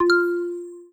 Xylo_13.wav